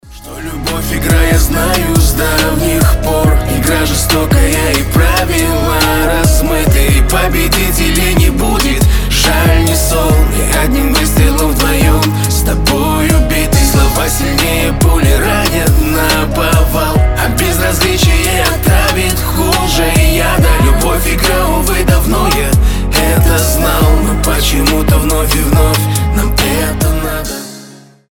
• Качество: 320, Stereo
лирика
грустные
дуэт